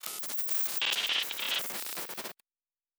pgs/Assets/Audio/Sci-Fi Sounds/Electric/Glitch 3_07.wav at master
Glitch 3_07.wav